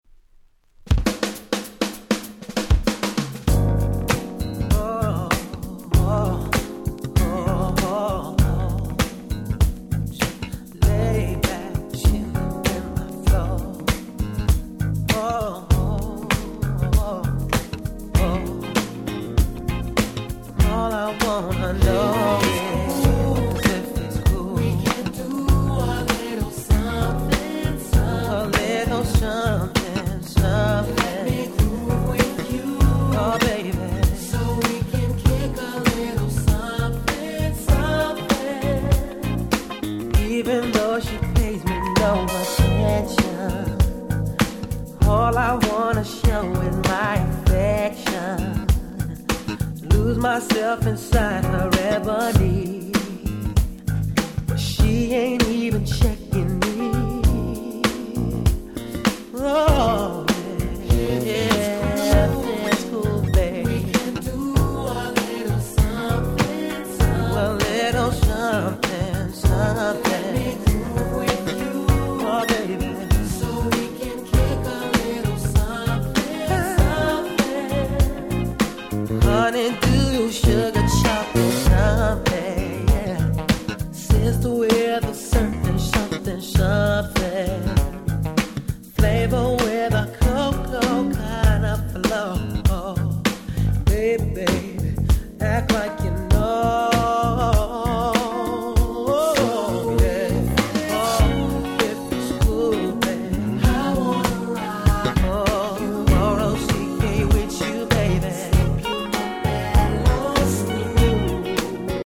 Nice Neo Soul♪
ムーディーで大人の雰囲気プンプンの超格好良い1曲です！
ネオソウル 90's R&B